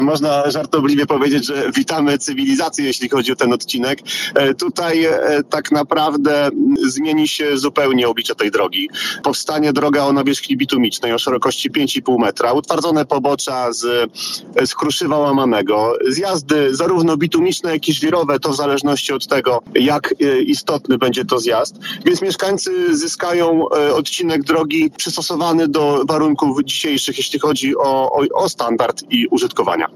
Powiat ostrołęcki otrzymał ponad 2 miliony złotych na przebudowę 1,5 kilometrowego odcinka na trasie Dąbek-Opęchowo. Wicestarosta powiatu Artur Kozłowski mówi, że to bardzo istotna inwestycja, bo w tej chwili jest to droga żwirowa.